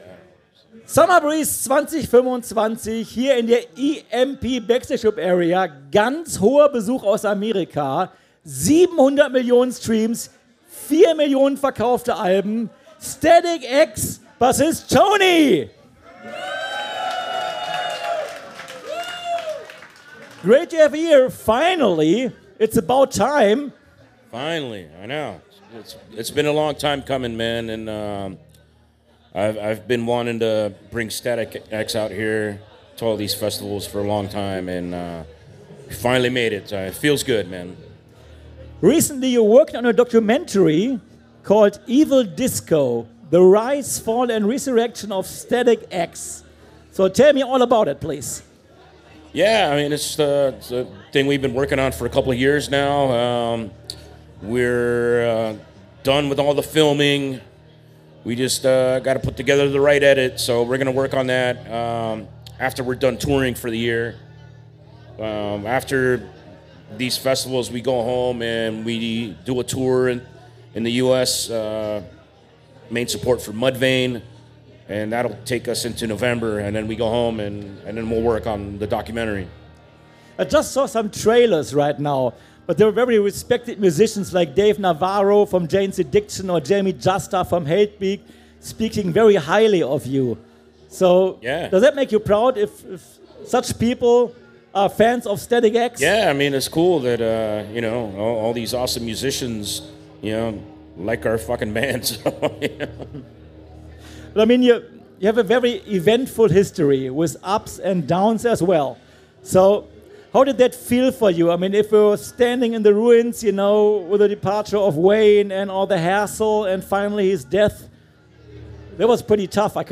Summer Breeze 2025 Special - Static-X - Live aus der EMP Backstage Club Area